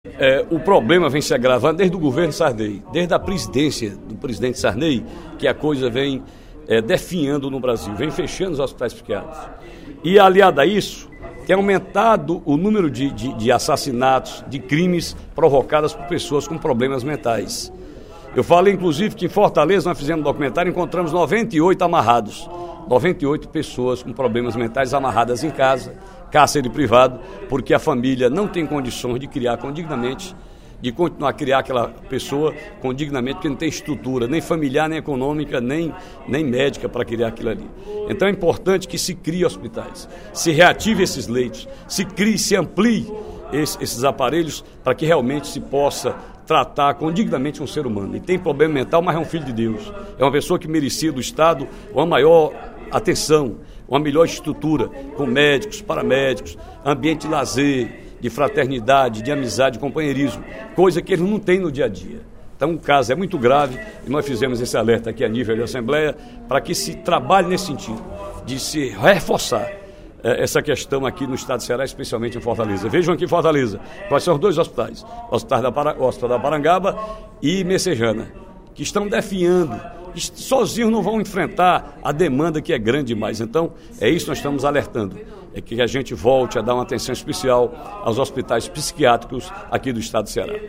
O deputado Ferreira Aragão (PDT) destacou, durante o primeiro expediente da sessão plenária da Assembleia Legislativa desta sexta-feira (06/06), o que considera como uma das maiores crises de identidade do Brasil.